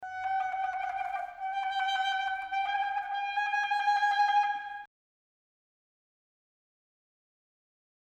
Quarter-Tone Trills and Tremolos